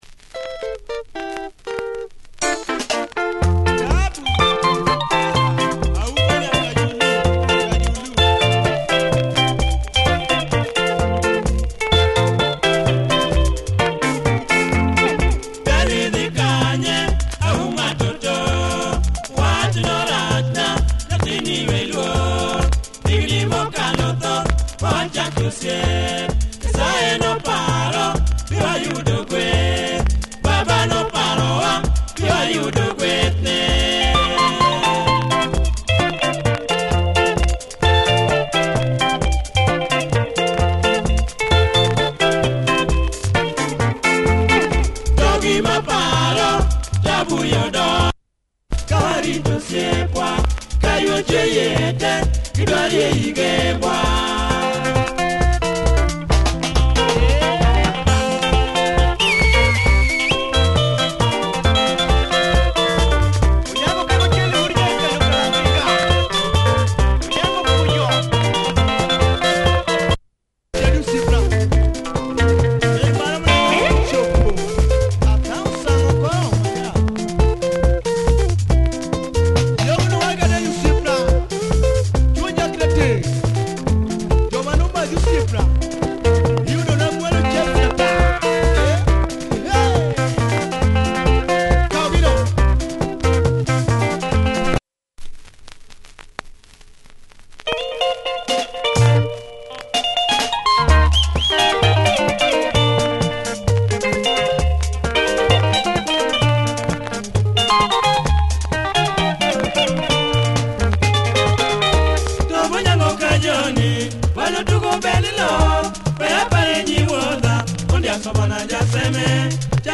Nice Luo benga by this group